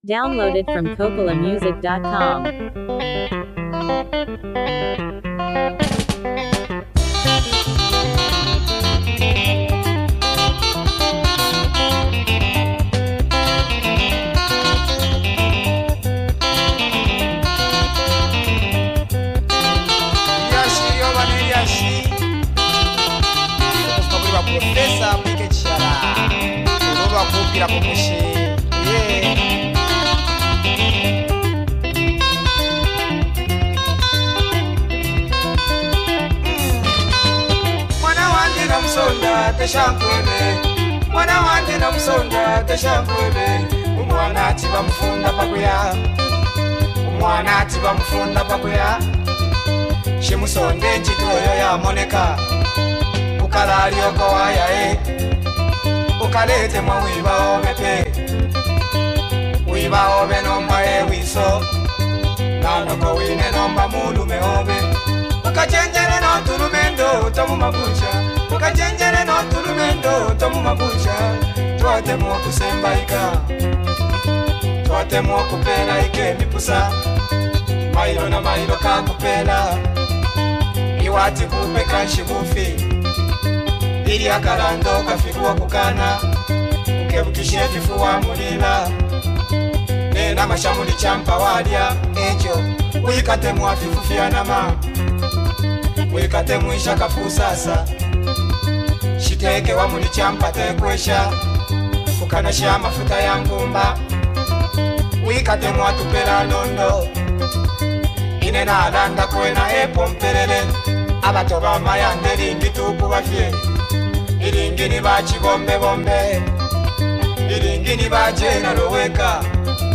reflective and emotionally rich Zambian song
With its smooth melody and relatable storytelling